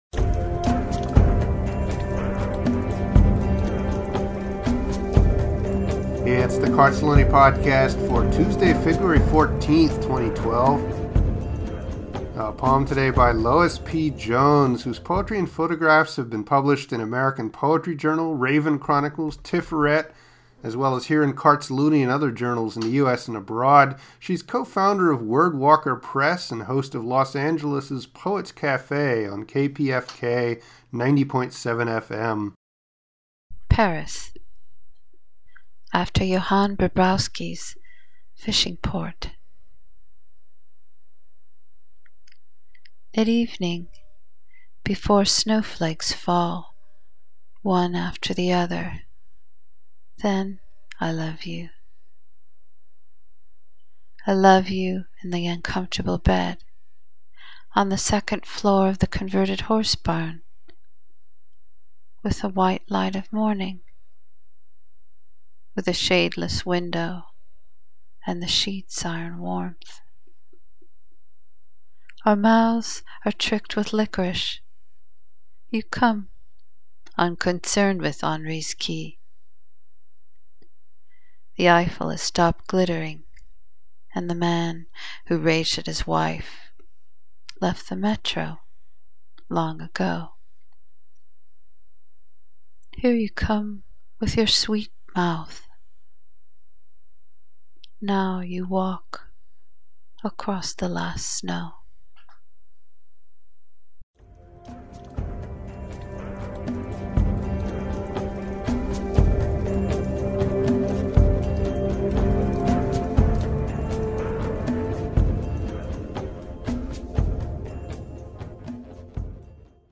I like your voice and pause…
Simply beautiful and your reading of it.. every word crystallized and poignant.